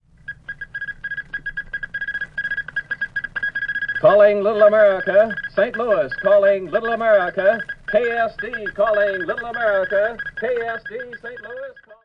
莫尔斯代码
描述：1940年开播的一个名为“向南极探险队致敬”的广播节目。